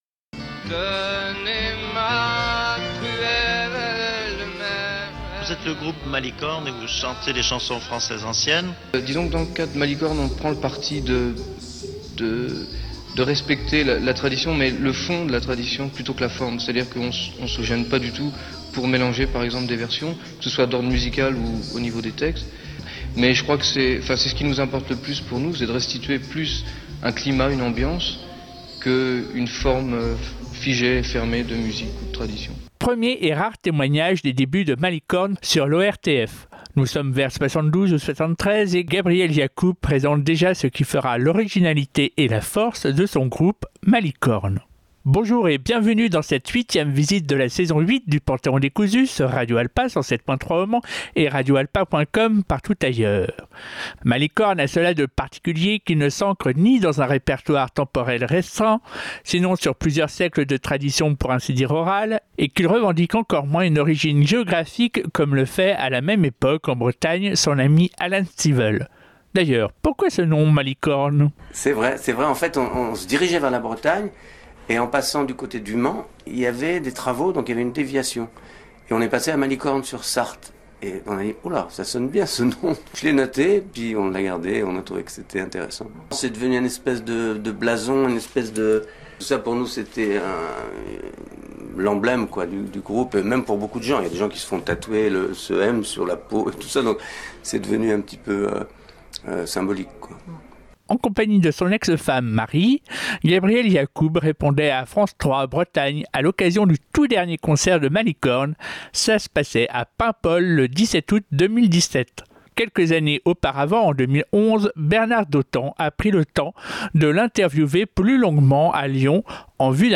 INTERVIEW MUSIQUE